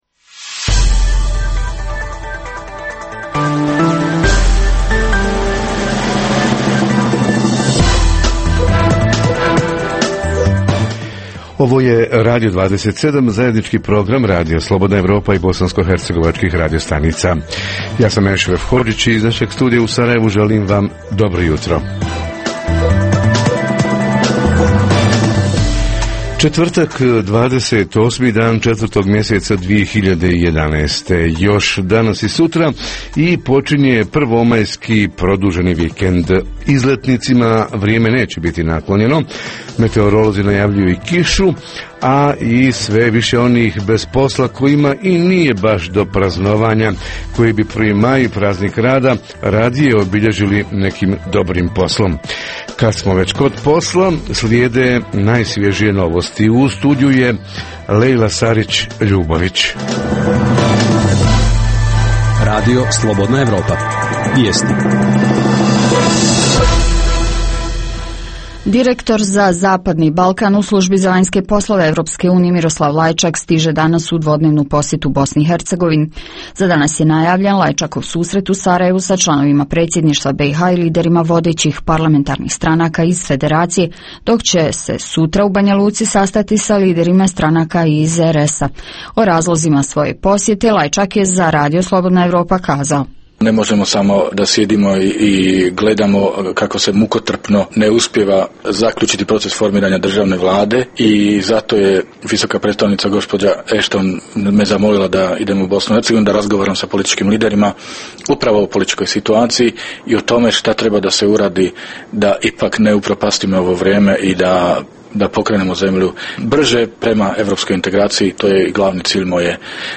Međunarodni praznik rada i petsto hiljada bh. radnika na evidenciji nezaposlenih - kako će nezaposleni slaviti Prvi maj? - Reporteri iz cijele BiH javljaju o najaktuelnijim događajima u njihovim sredinama.
Redovni sadržaji jutarnjeg programa za BiH su i vijesti i muzika.